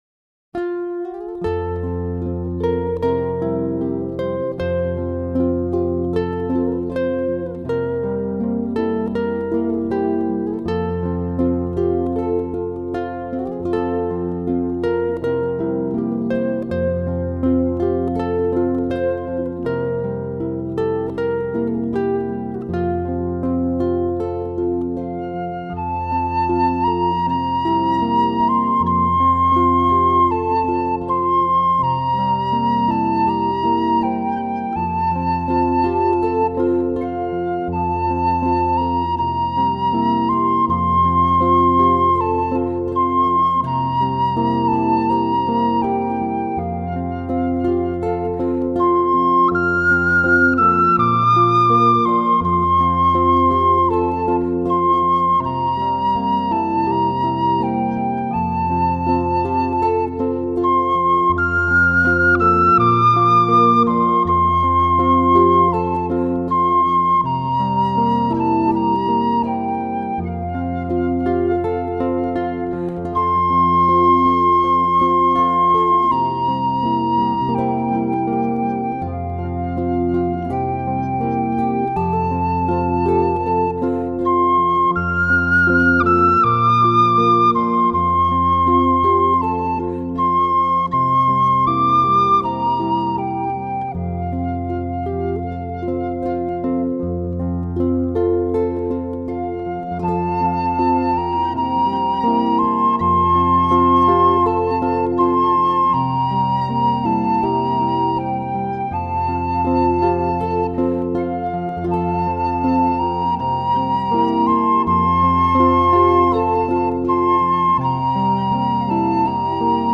よし笛の爽やかで澄みきった音色を聴かれたことはありますか？
（F管で約２オクターブの音域）
琵琶湖を渡る風を思わせる癒やしの優しい音色は、さながら琵琶湖からの贈り物。
よし笛の音色のサンプルとして “ほっとらいん” による演奏をお聴きください。